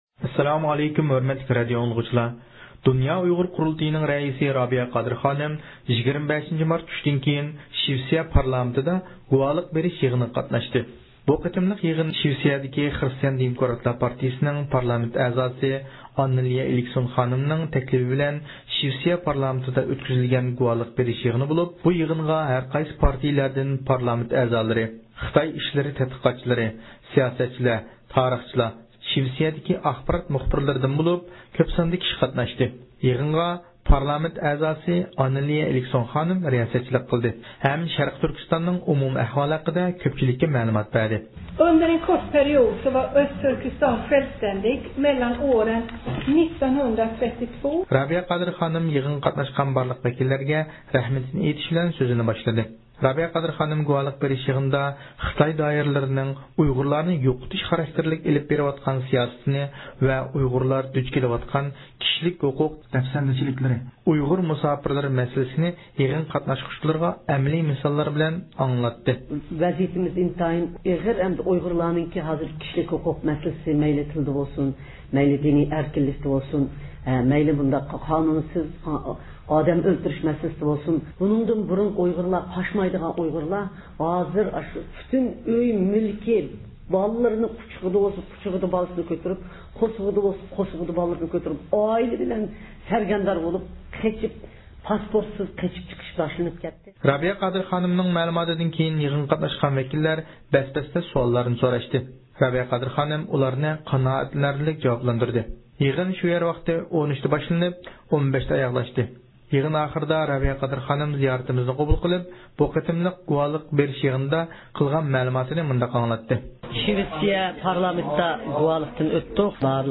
رابىيە خانىم زىيارىتىمىزنى قوبۇل قىلىپ، گۇۋاھلىق بېرىش يىغىنى ھەققىدە مەلۇمات بەردى.